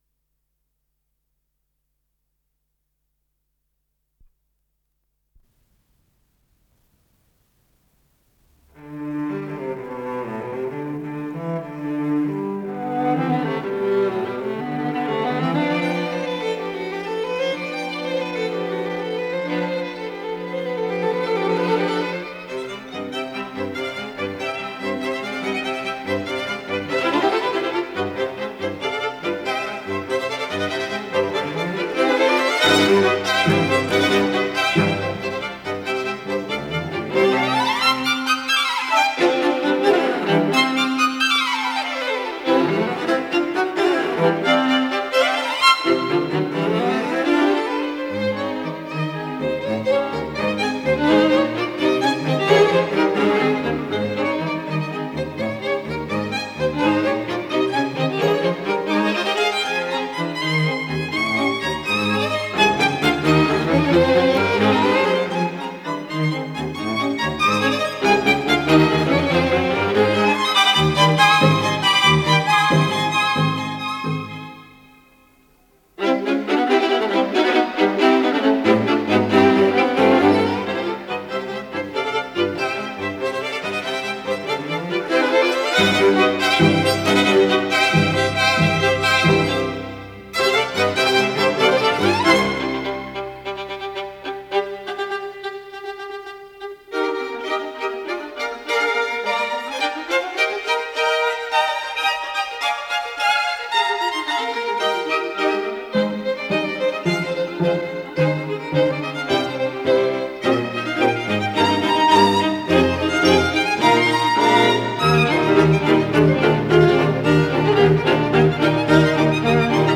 Аллегретто
1-я скрипка
альт
виолончель
ВариантДубль моно